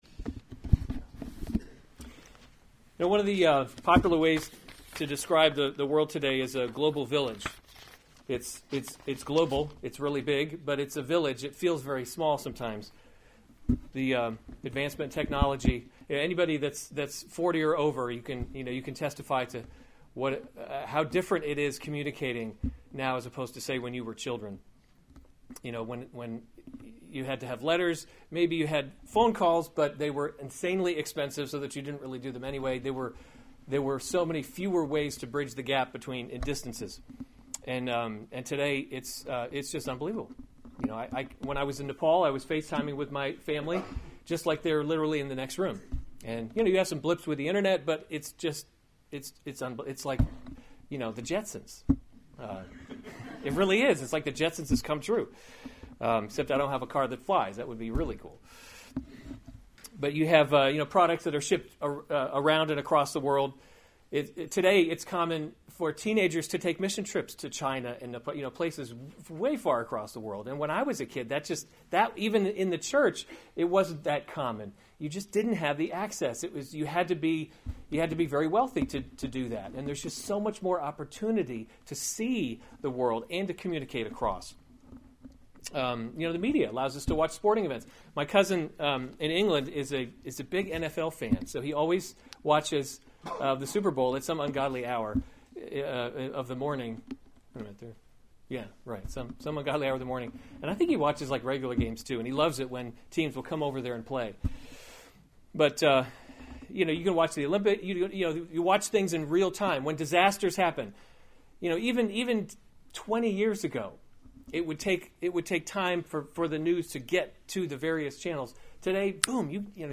March 4, 2017 1 Timothy – Leading by Example series Weekly Sunday Service Save/Download this sermon 1 Timothy 2:1-7 Other sermons from 1 Timothy Pray for All People 2:1 First of […]